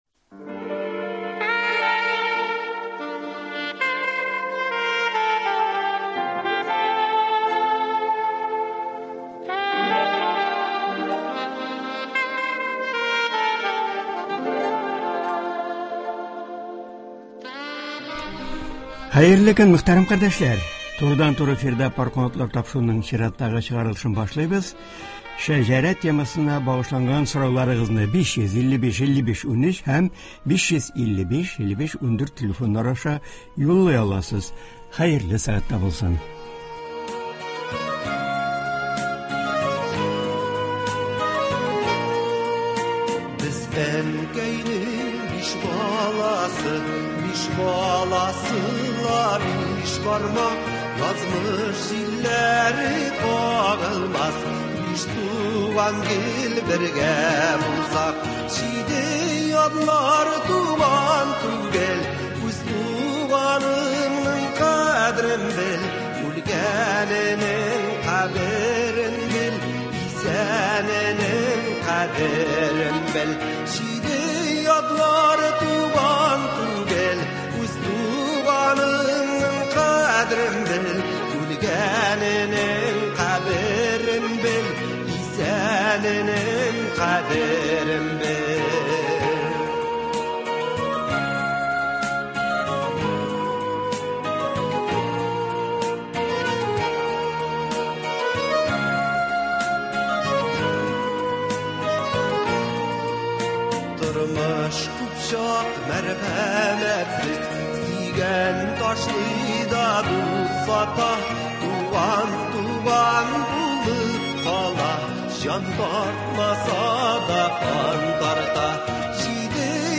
Болар хакында турыдан-туры эфирда дәрәҗәле дин белгече
тыңлаучылар сорауларына җавап бирәчәк.